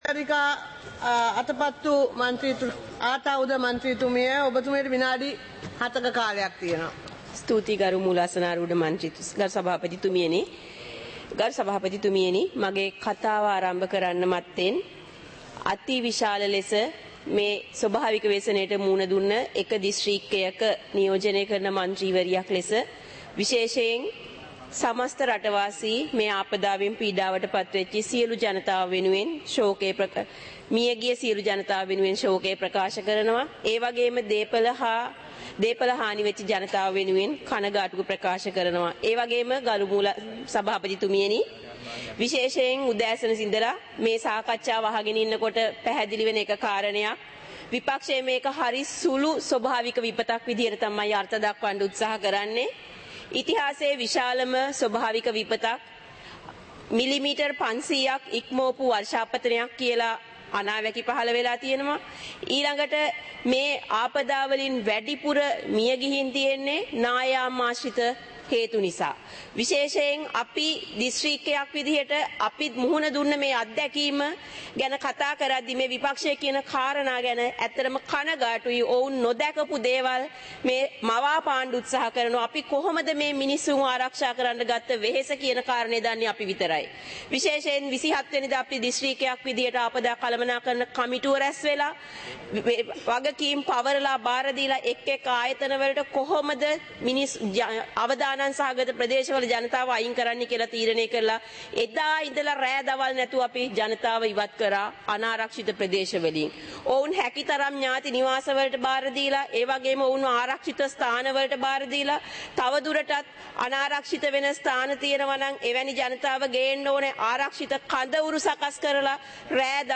சபை நடவடிக்கைமுறை (2025-12-05)